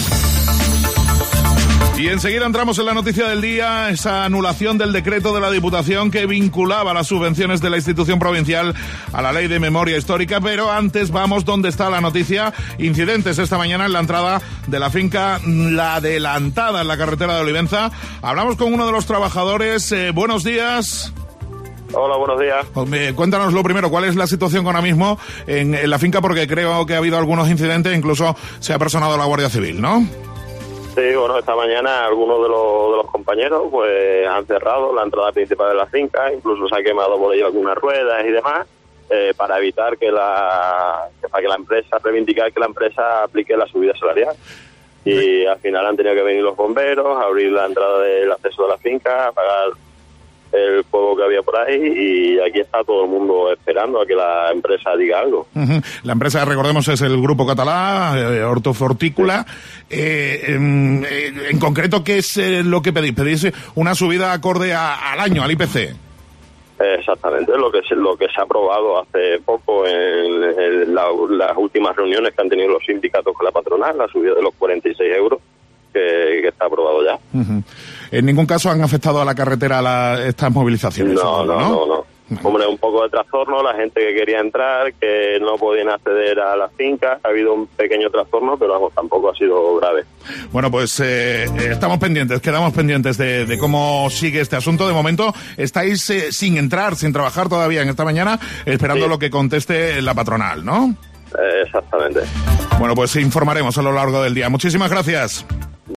La COPE Badajoz ha conectado en directo con uno de los trabajadores que, desde la Finca ha explicado la situación
La Cadena COPE ha abierto sus Informativos Matinales con esta noticia mediante una entrevista telefiónioca a uno de los trabajadores que permanecen en la Finca.